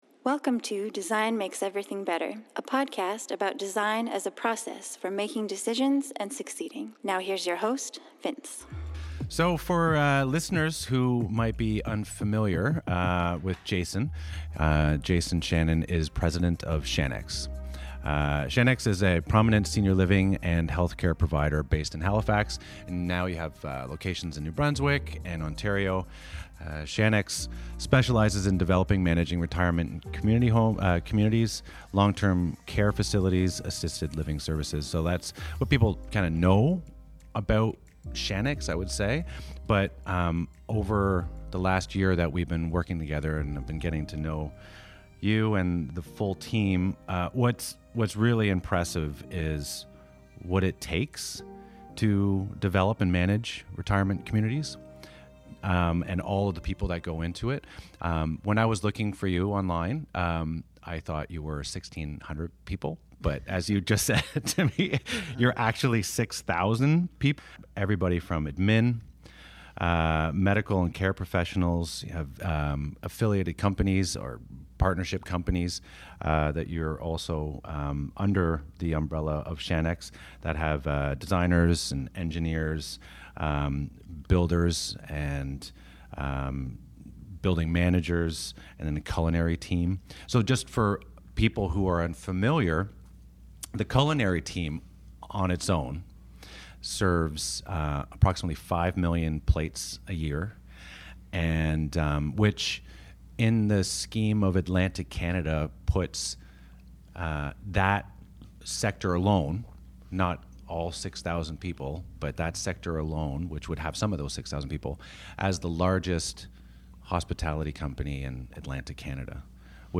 In conversation: Origin of the family business